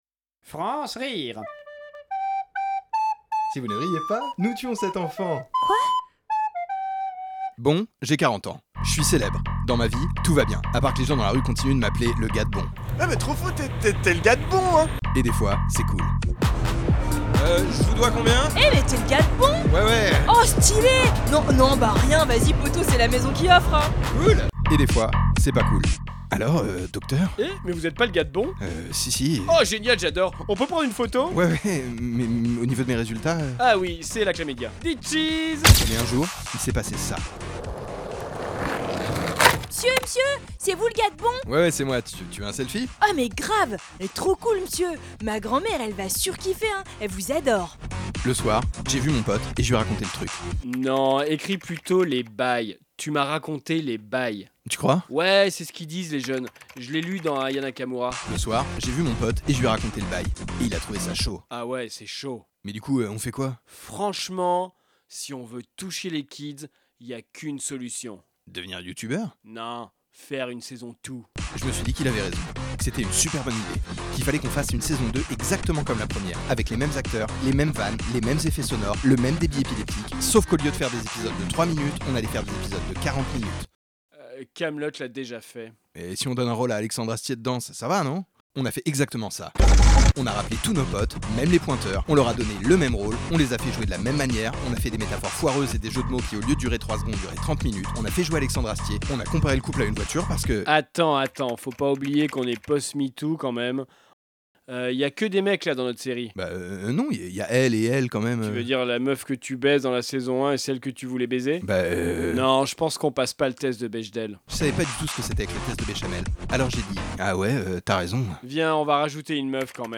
Création sonore
Bre... Bon, on a fait une parodie.